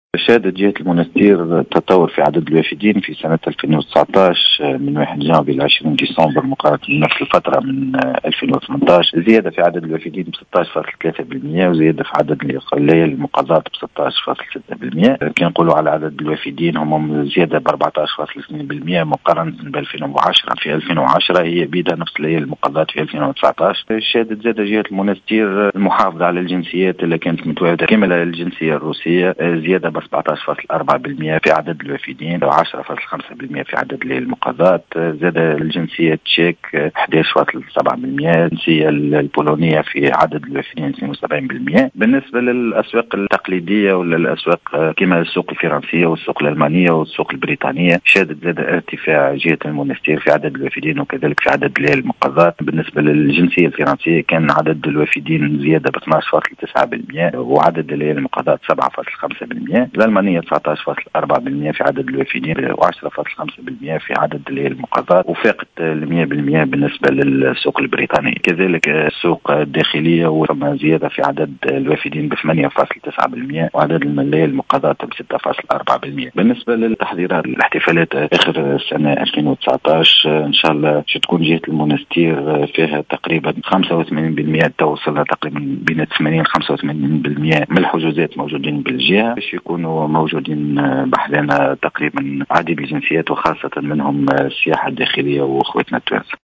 و أضاف بن حليمة في تصريح للجوهرة أف أم، ارتفاع عدد الوافدين من السوق الداخلية بنسبة 8.9% ، و بـ 6.4% في عدد الليالي المقضاة.